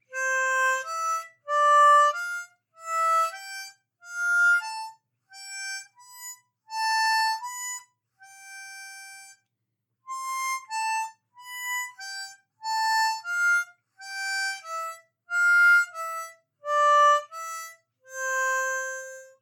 C-Major-Couplets-4h-Accented.mp3